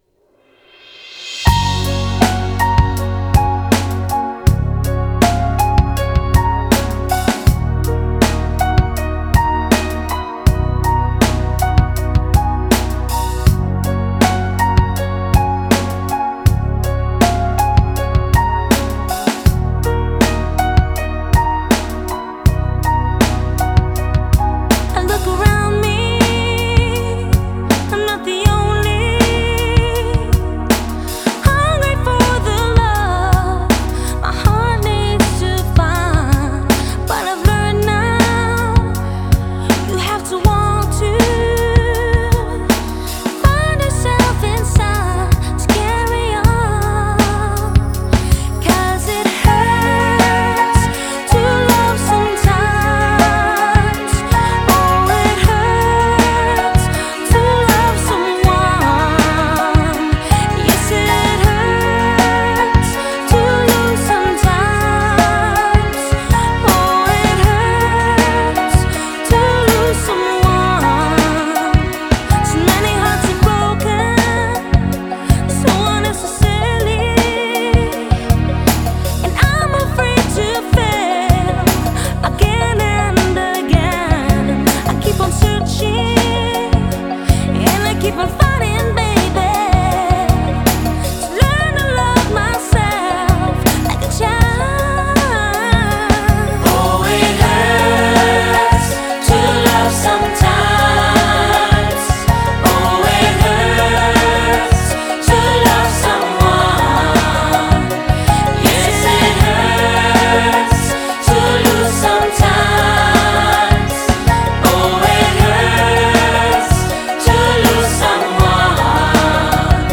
европейская фолк/рок группа